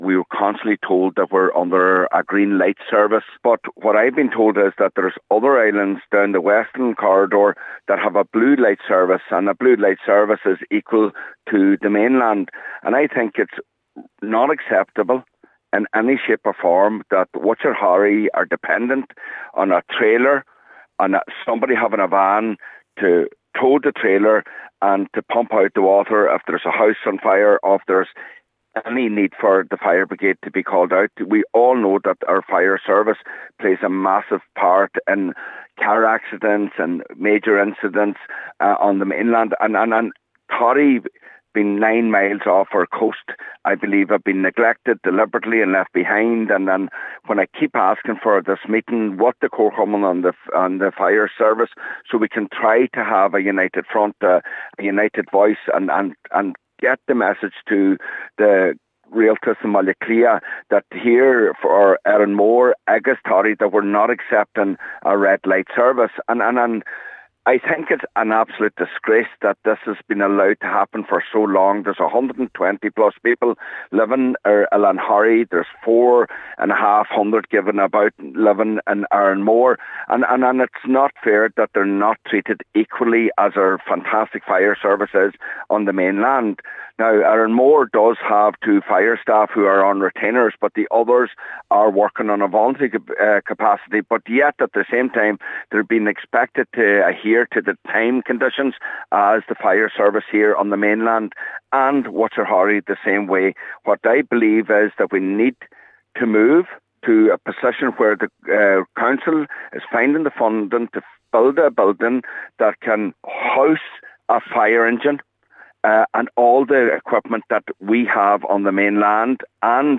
Cllr MacGiolla Easbuig told the meeting the islands off Donegal have a scaled down ‘red light’ emergency service.